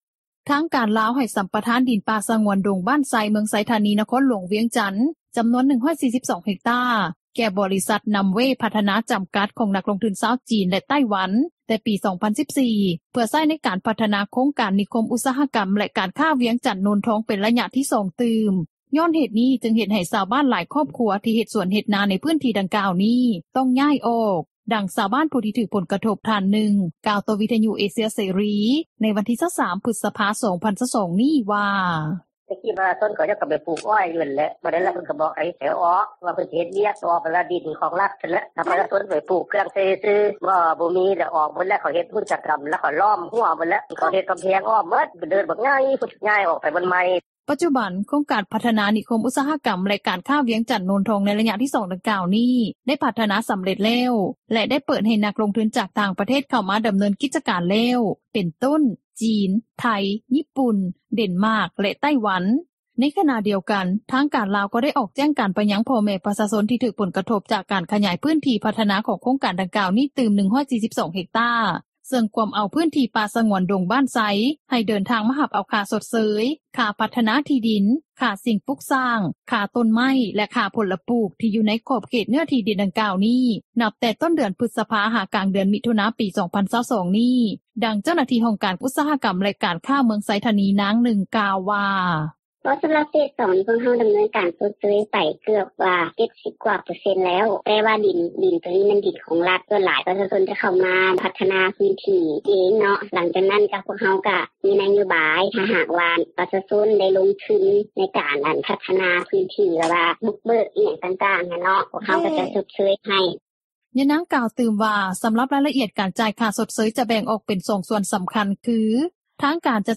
ດັ່ງຊາວບ້ານຜູ້ຖືກຜົລກະທົບ ທ່ານນຶ່ງ ກ່າວຕໍ່ວິທຍຸເອເຊັຽເສຣີ ໃນວັນທີ່ 23 ພຶສພາ 2022 ນີ້ວ່າ:
ດັ່ງຊາວບ້ານຜູ້ຖືກຜົລກະທົບ ຈາກໂຄງການໃນໄລຍະທໍາອິດ ກ່າວວ່າ: